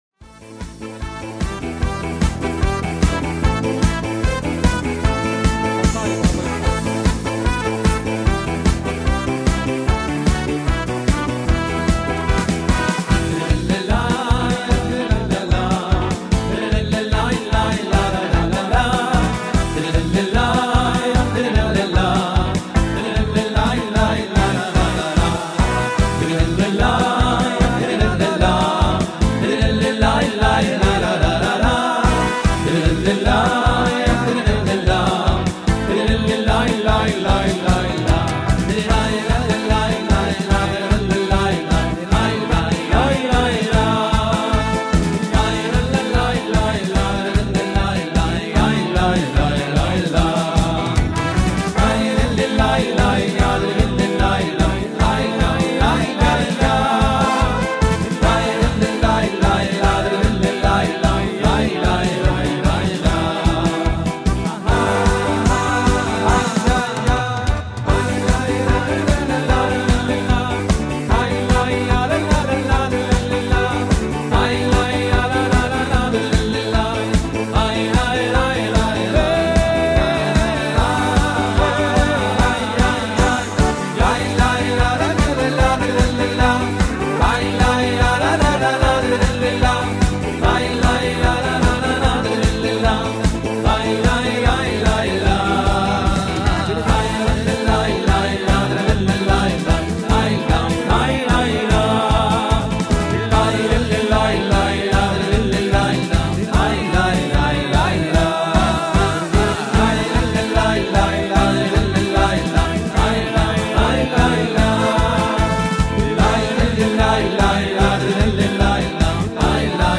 coro masculino